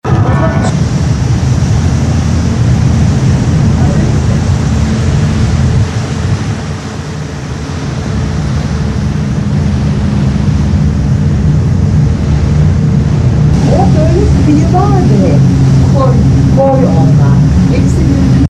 Removing ship engines humming noise - VideoHelp Forum
I have a video clip taken on the deck of a ship during a cruise. There is the continuous humming noise of the ship engines.
Most of the rumbling comes from the 100hz and below range. Here's your audio with the <100hz lows pumped up to hear it.
The hissing is in the 4K-7K range